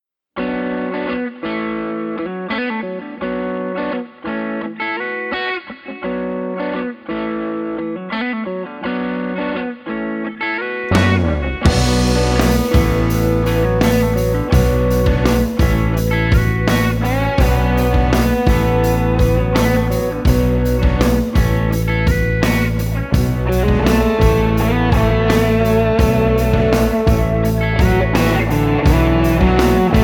Lead Guitar and Rhythm Guitar